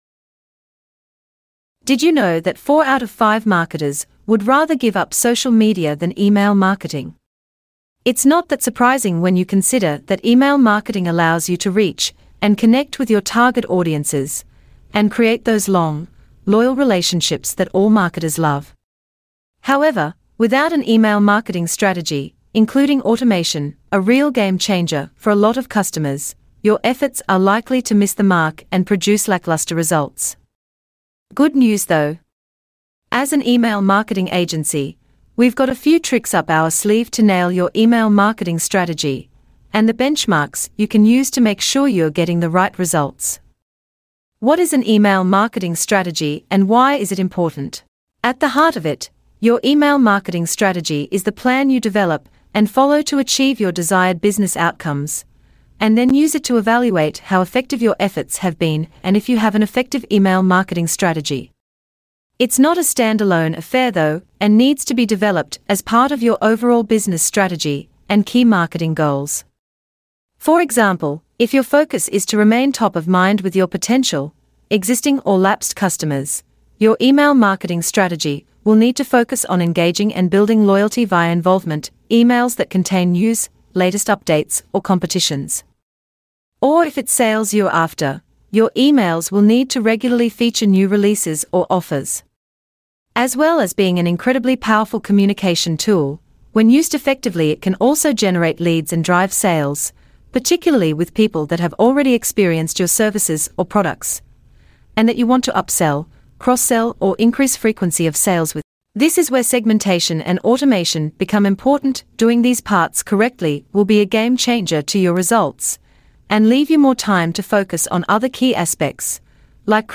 Listen to a voice recording: Reading time